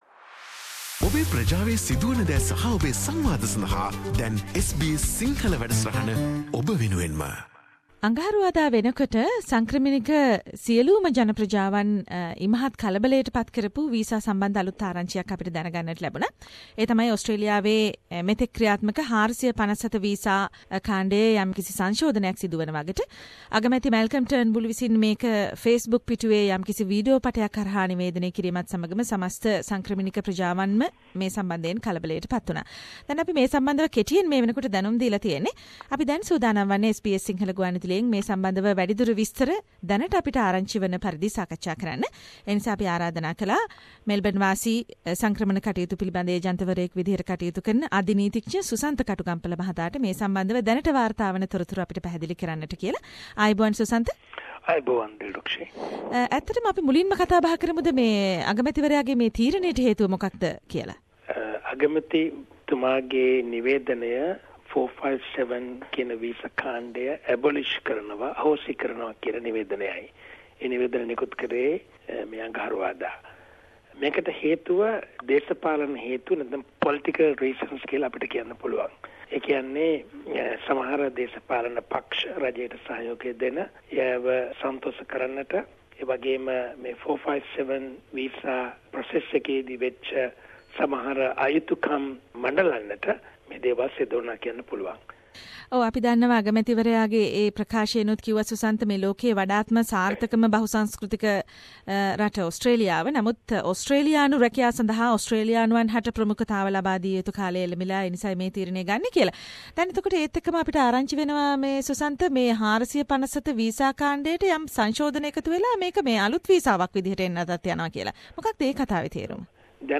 මෙම සම්මුඛ සාකච්චාවෙන්...